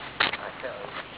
mausoleum Evp's
Thess EVP's were recorded at a location that must remain undisclosed, ALL we can tell you is that it is somewhere in Utah, and it is by far a very active place.
We were outside having a smoke break and we recorded this.